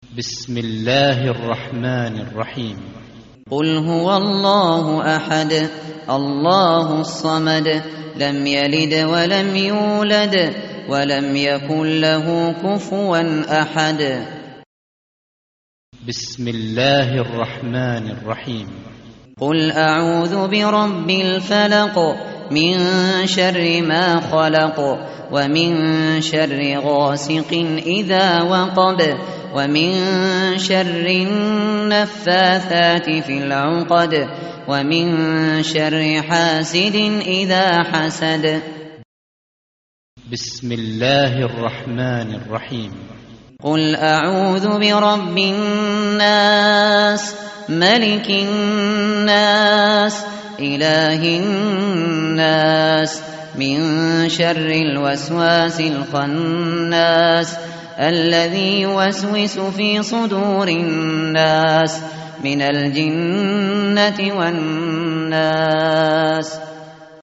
tartil_shateri_page_604.mp3